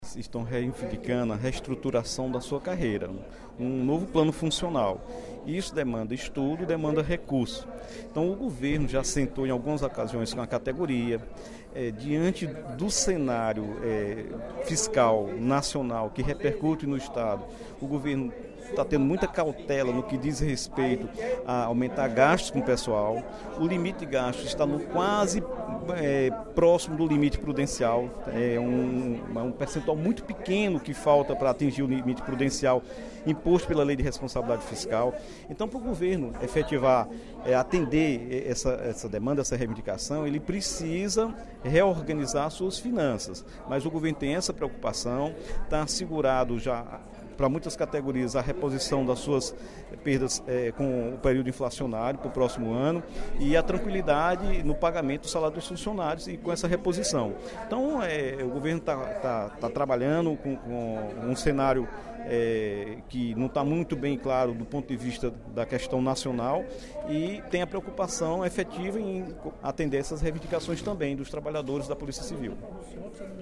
O deputado Dr. Santana (PT), em pronunciamento no primeiro expediente da sessão plenária desta quinta-feira (22/10), abordou as demandas da Polícia Civil apresentadas por meio da carta “Por um Ceará Pacífico”, do Sindicato da Polícia Civil do Ceará (Sinpol-CE).